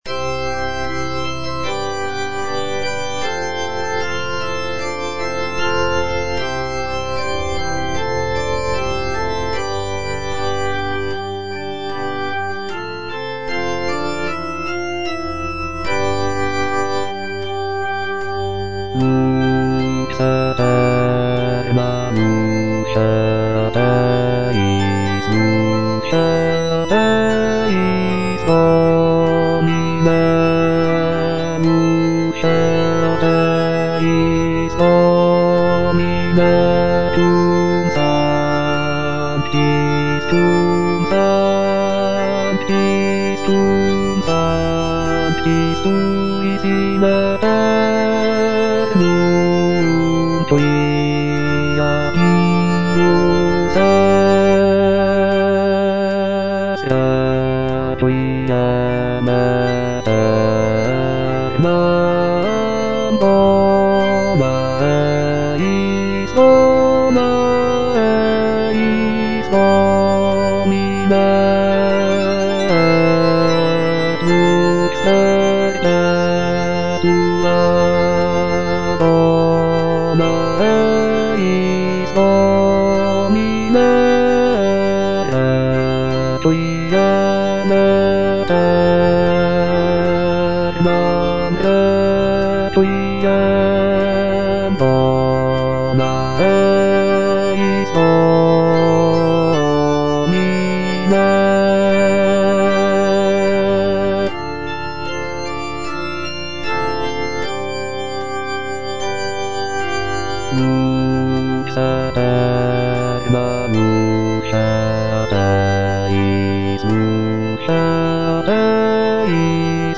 (bass II) (Voice with metronome) Ads stop